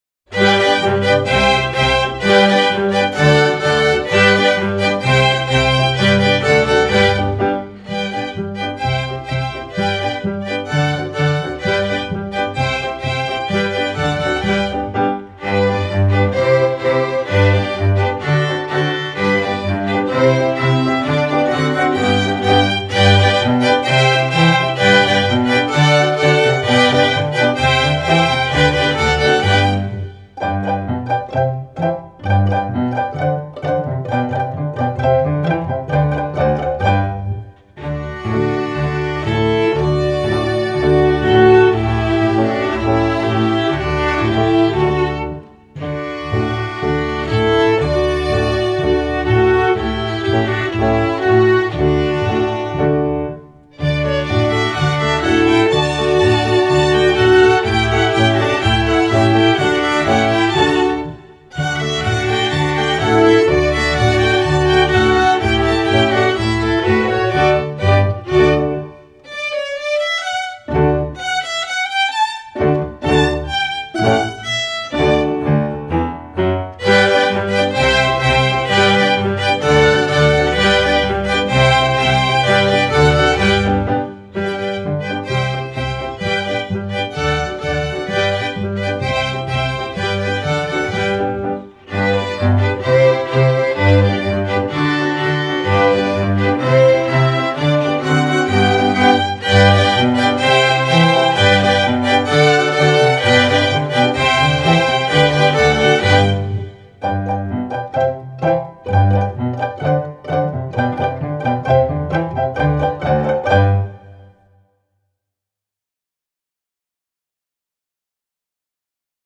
instructional, children
Piano accompaniment part: